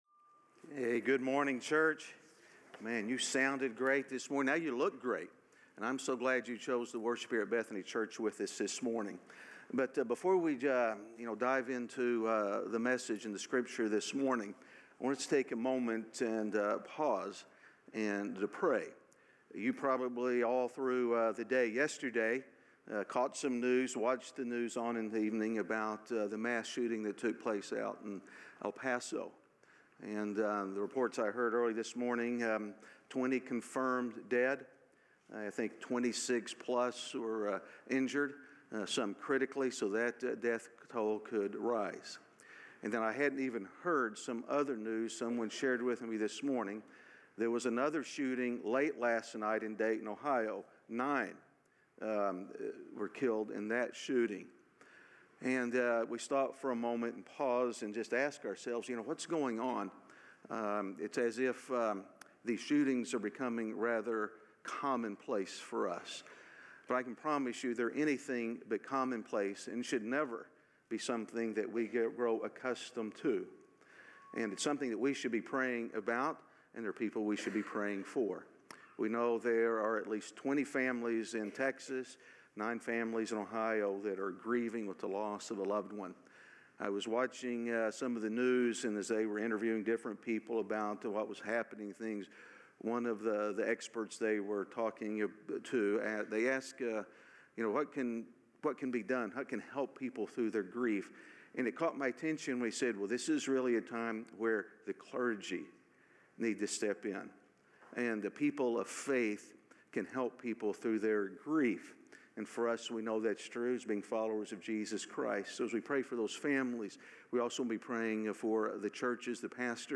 A message from the series "The Walk."